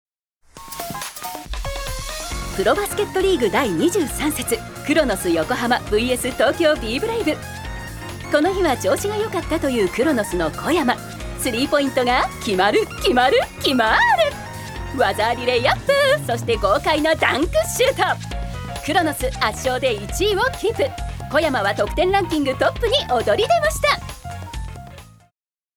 女性タレント
ナレーション６